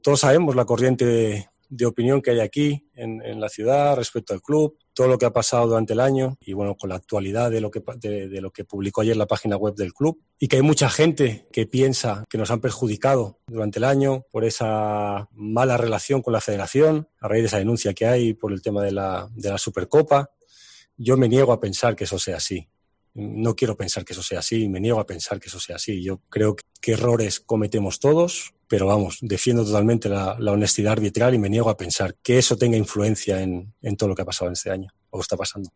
Albert Celades ha comparecido en rueda de prensa telemática en la previa del choque ante Osasuna.